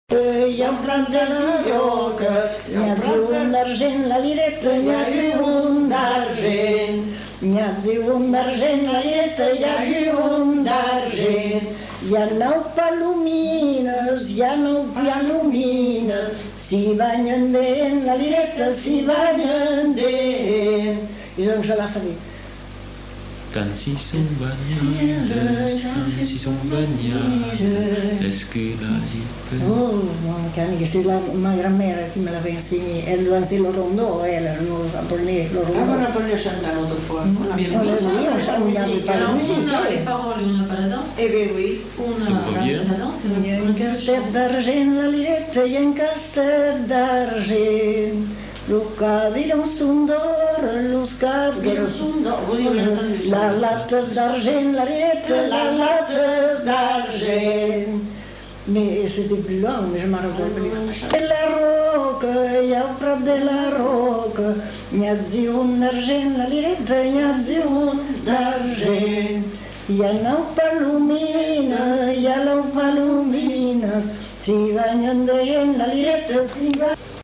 Lieu : Mont-de-Marsan
Genre : chant
Effectif : 2
Type de voix : voix de femme
Production du son : chanté
Danse : rondeau